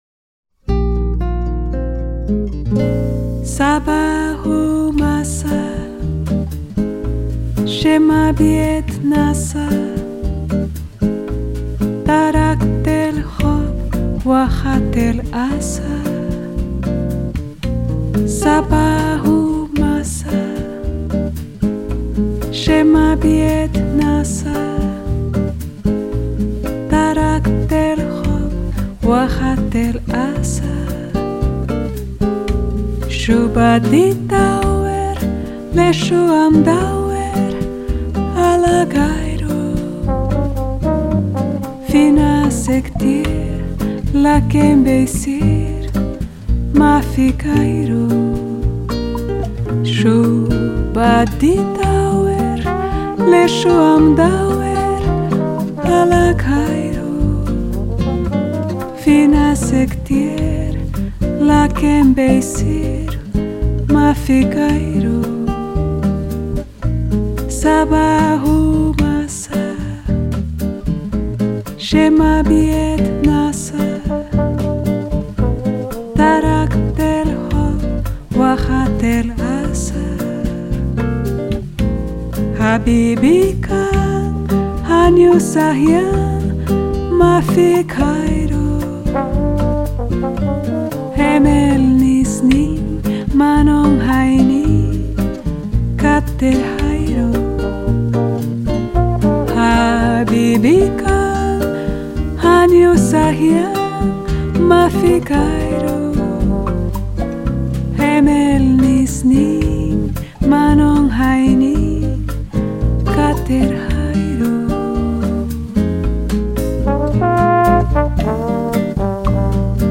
閒適怡人、無拘無束的音樂，搭配上她時而慵懶柔軟、時而活潑俏皮的唱腔，
音樂類型：爵士樂 - Bossa Nova[center]